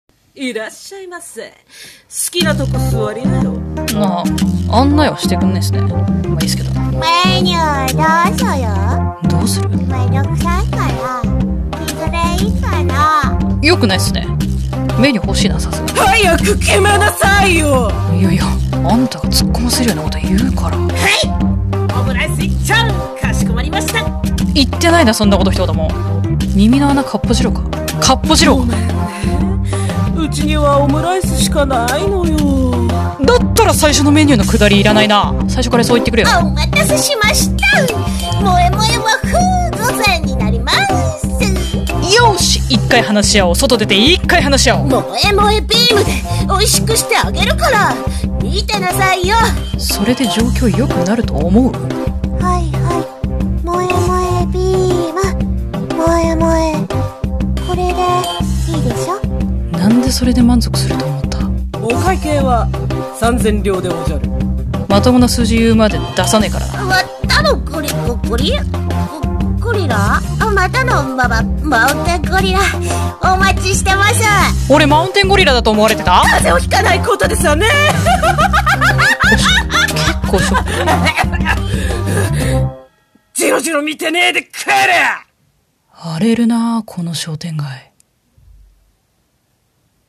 アドリブ声劇「多重人格喫茶」